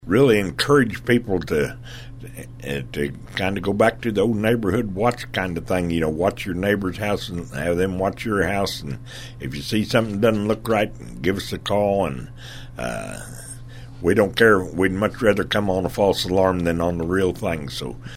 (Farmington, MO.) Daytime burglaries have been happening in St. Francois County over the past several weeks now that the weather is getting warmer. St Francois County Sheriff Dan Bullock says it's a good time to implement a neighborhood watch program in your area.